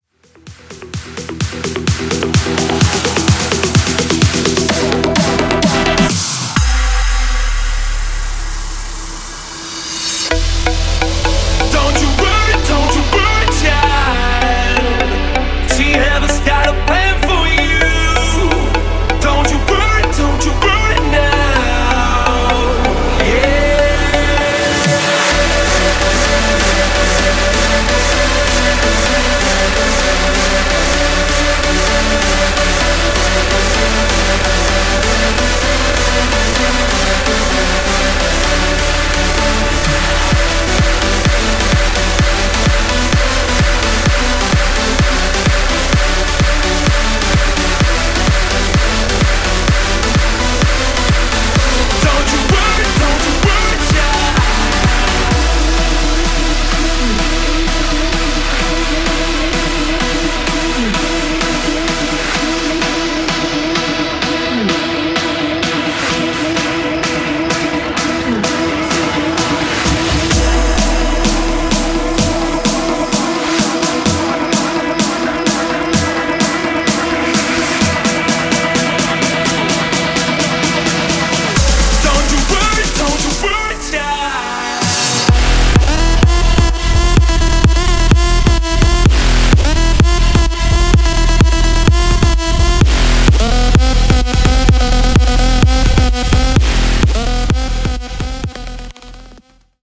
Bigroom House Edit)Date Added